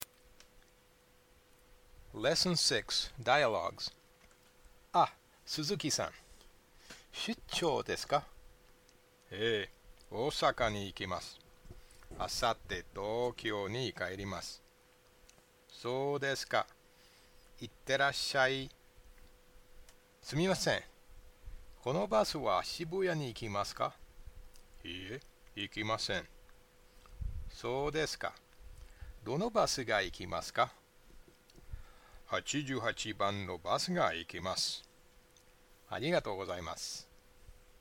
Japanese JFBP L6.2 dialogues